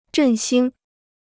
振兴 (zhèn xīng) Free Chinese Dictionary